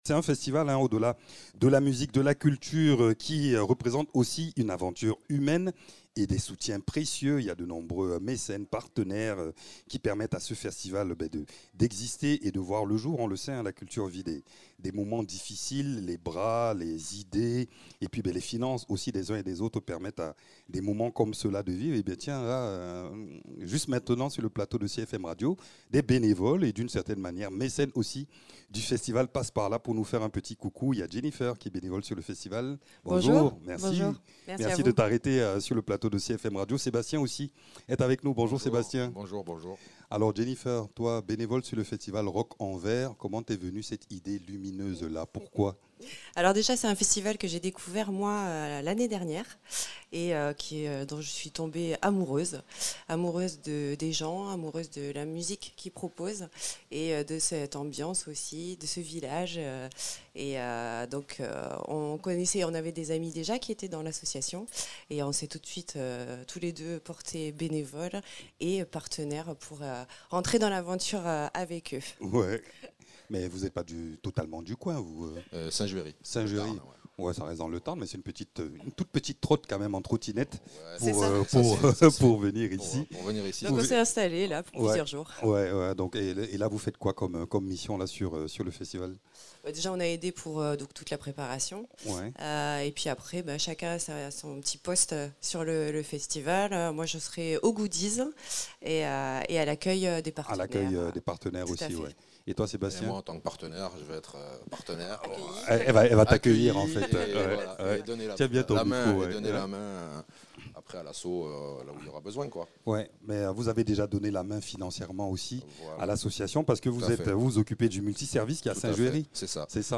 partenaires et bénévoles à Rock en Vère.